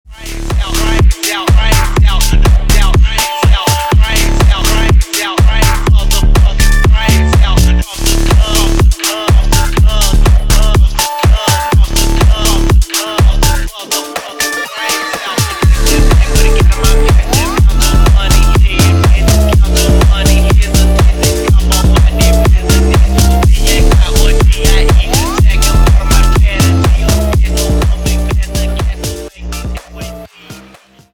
зарубежные клубные громкие